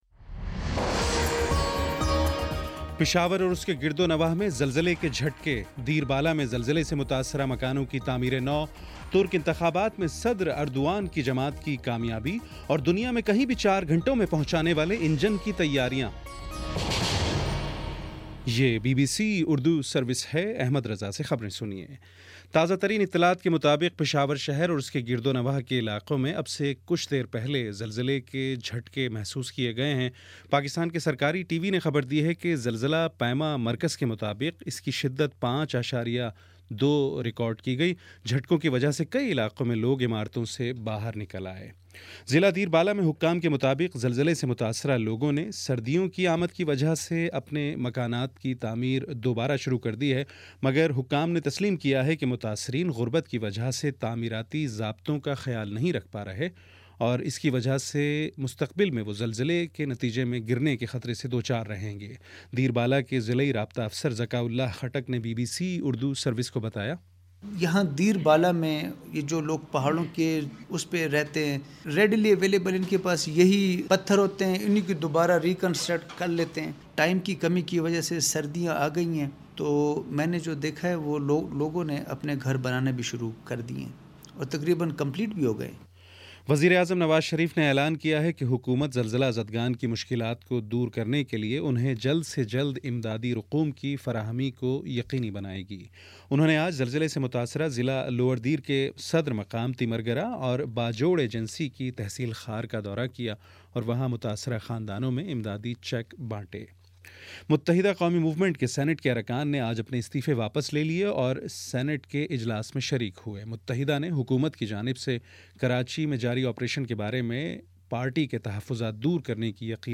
نومبر 02: شام سات بجے کا نیوز بُلیٹن